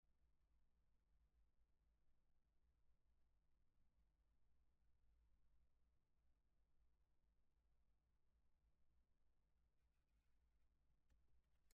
Male
Madrid nativo
Commercial.mp3
Microphone: Neumann Tlm 103